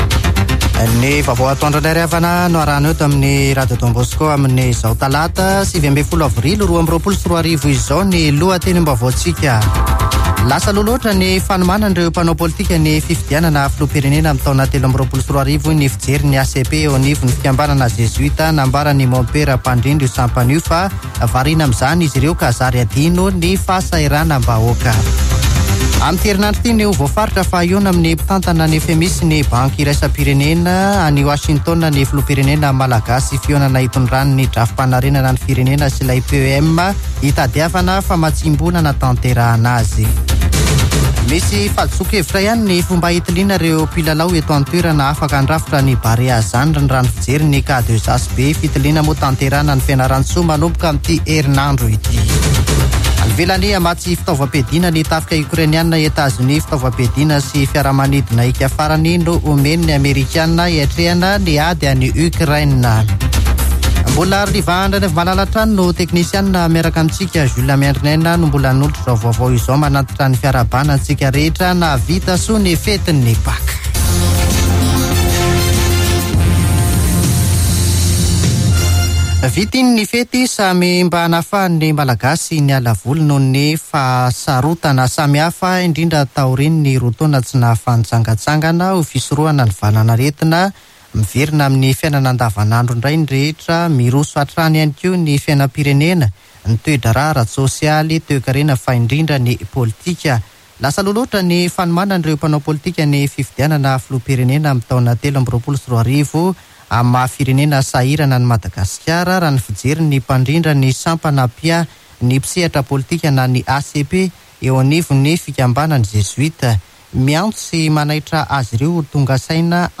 [Vaovao antoandro] Talata 19 aprily 2022